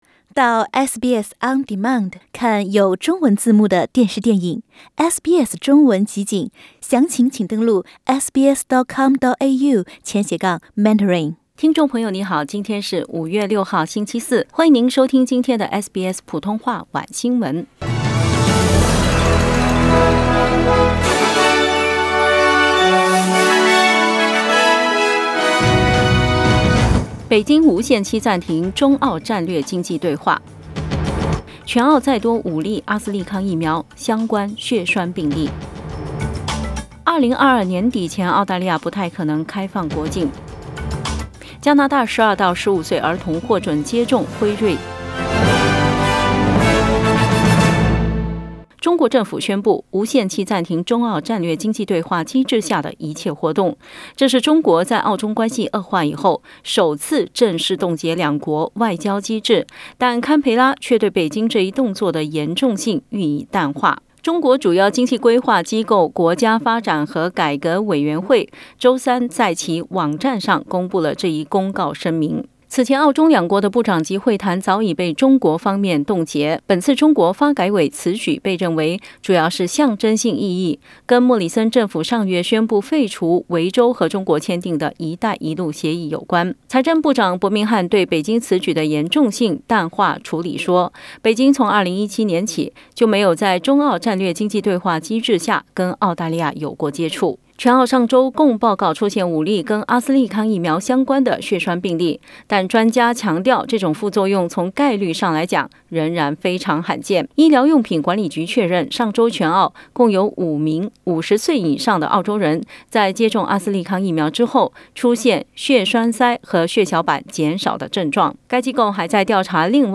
SBS Mandarin evening news Source: Getty Images